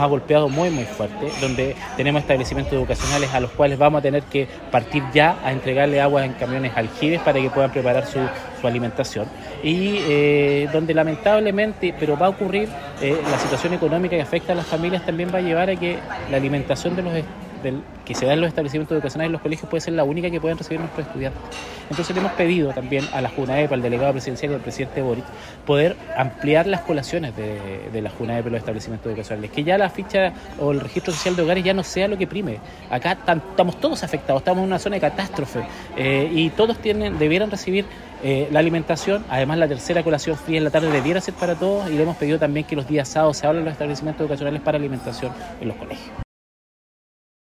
Y es que en el marco de la ceremonia del inicio año escolar provincial realizado el miércoles 13 de marzo en la escuela Alejandro Chelén Rojas, el alcalde Cristian Herrera Peña solicitó expresamente a las autoridades regionales que estuvieron presentes -Delegada Provincial Presidencial, Marily Oviedo, la Seremi de Educación, Cecilia Ramírez y la Delegada Provincial del Limarí de la Junaeb- la ampliación de raciones de alimentos para todos los niños que asisten a escuelas públicas de la comuna.